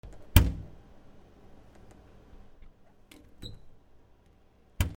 冷蔵庫